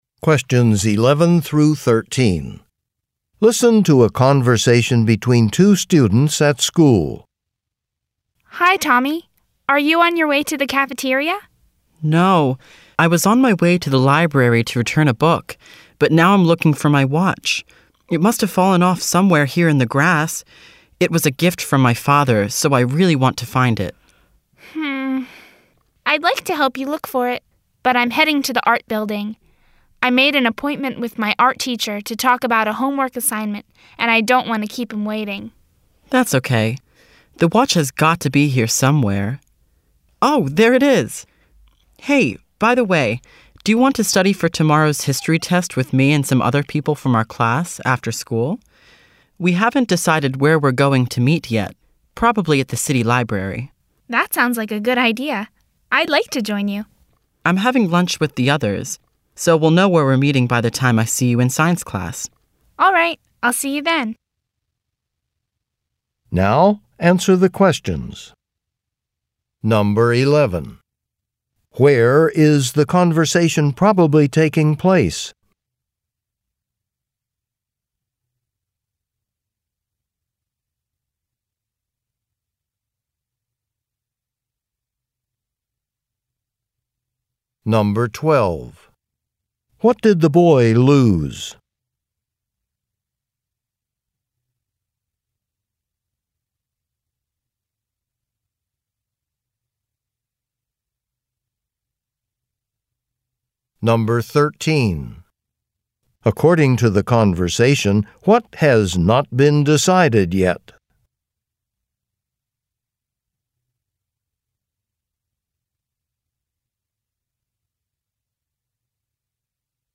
Conversation and Questions 11–13